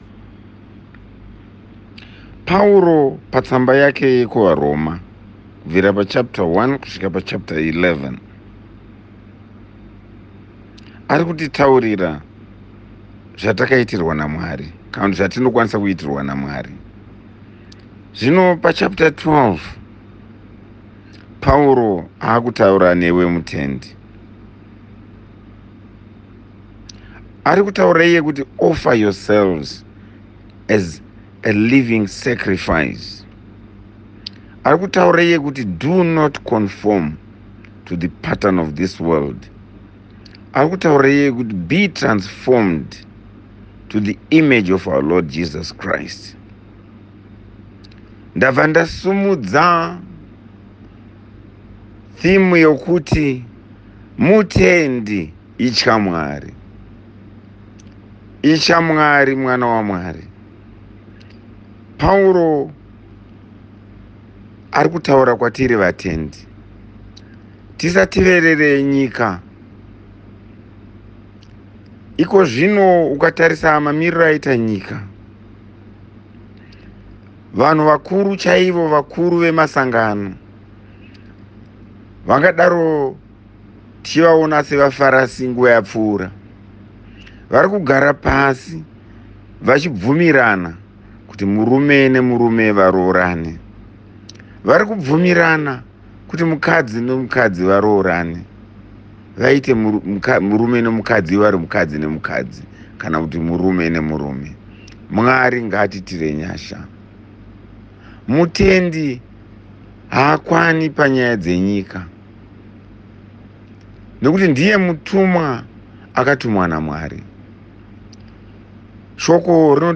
Borrowdale Methodist Church > Devotions > Transformed Life